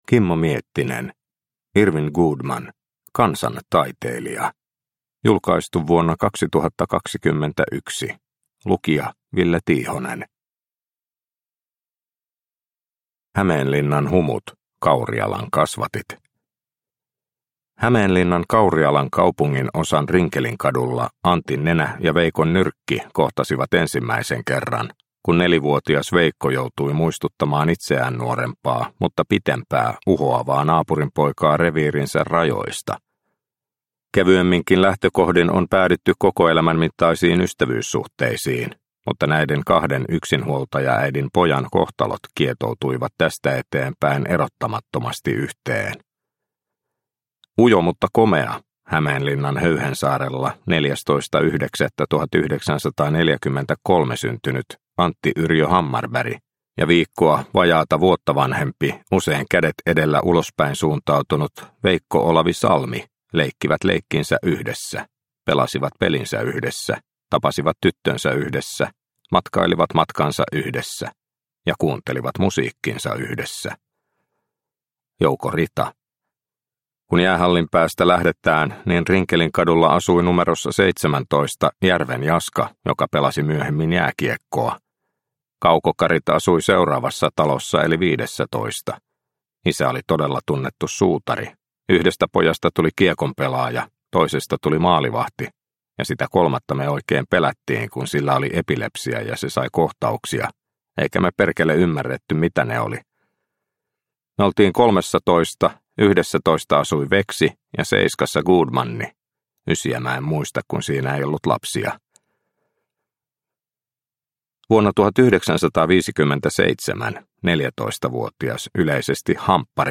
Irwin Goodman - kansan taiteilija – Ljudbok – Laddas ner